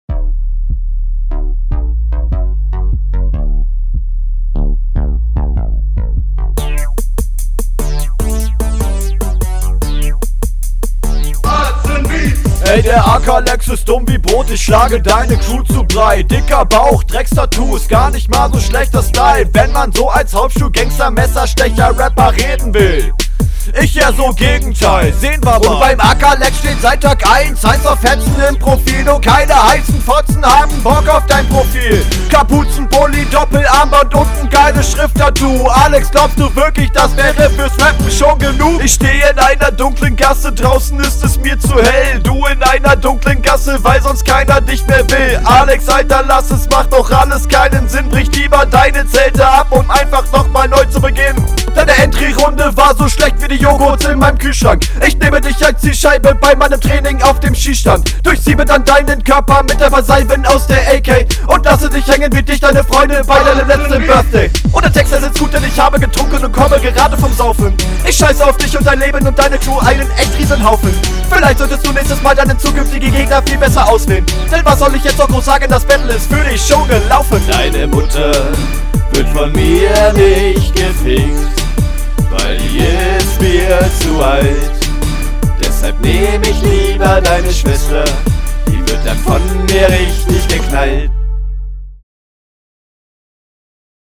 Mir gefällt an der Runde vor allem der Flow.